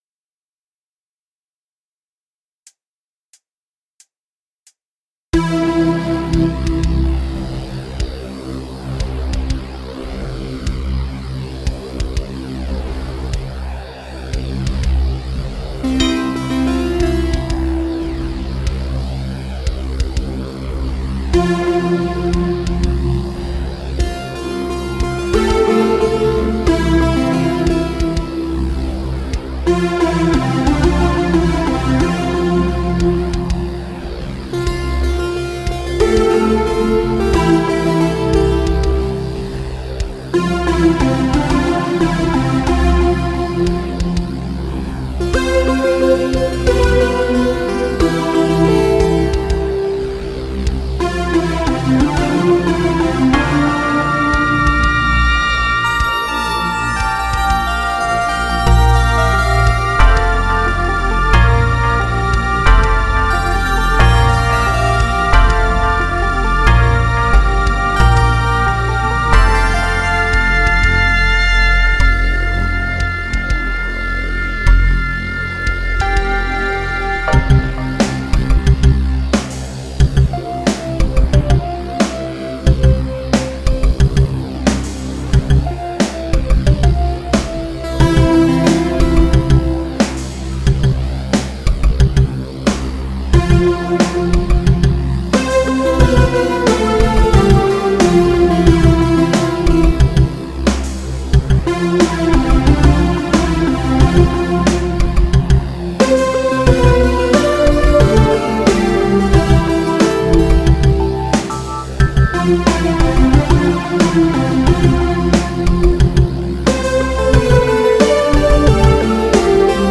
Ich habe hier zwei Klassiker des Synthpop ausgesucht.